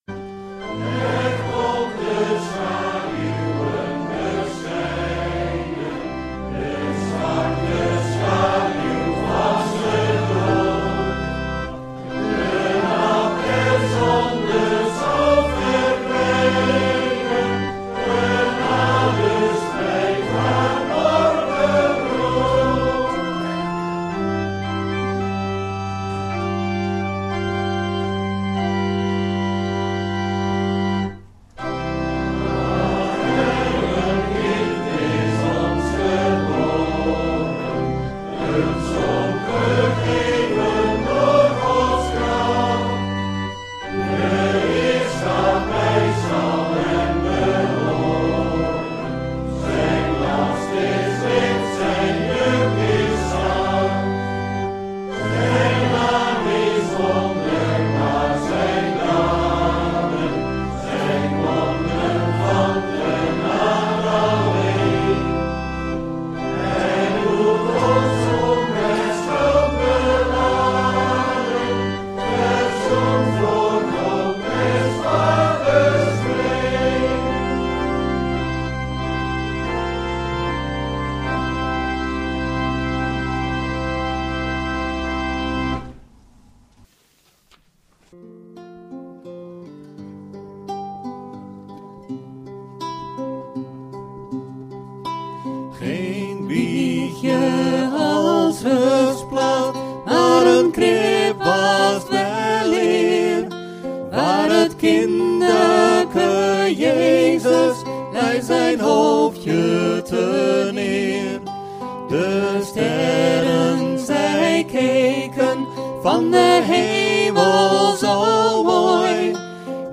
kerstnachtdienst